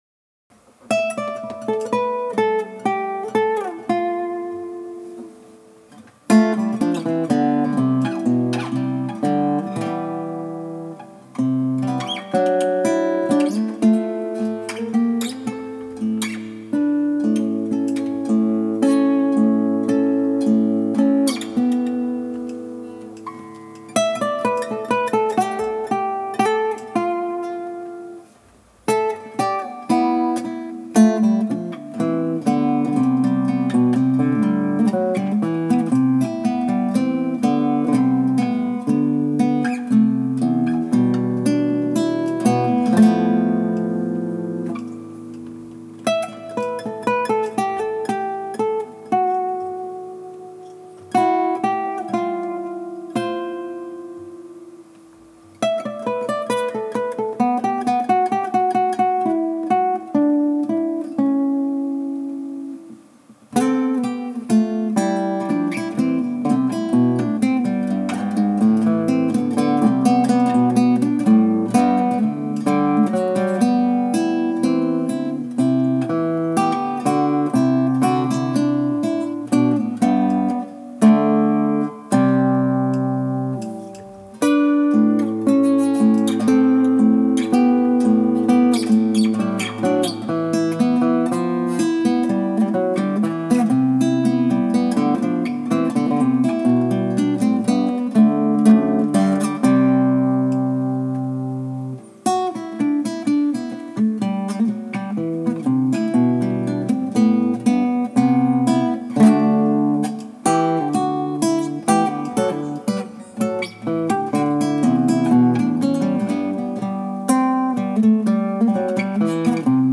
When the strings had settled, I did some noodling that evolved into a pseudo-classical thing that I finally put down as an MP3. It has one or two rough spots, but I think I might edit it a little and use it as soundtrack for a video at some point.
Instrumental